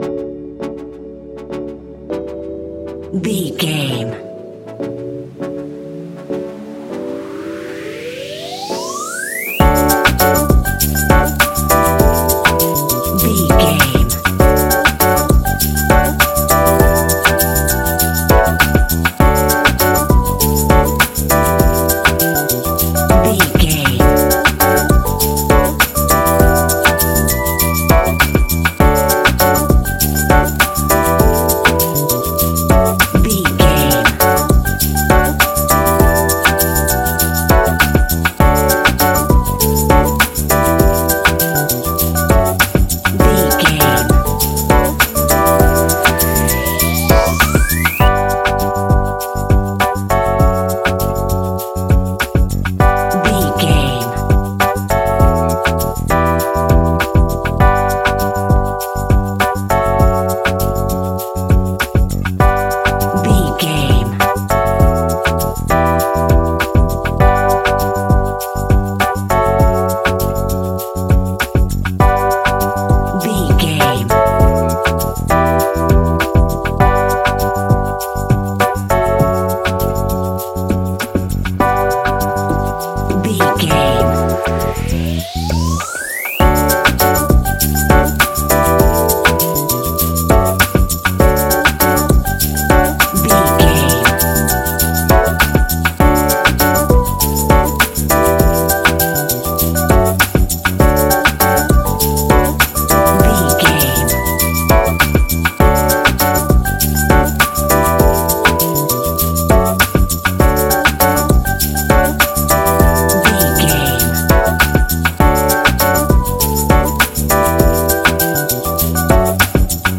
Ionian/Major
D♭
chilled
laid back
Lounge
sparse
new age
chilled electronica
ambient
atmospheric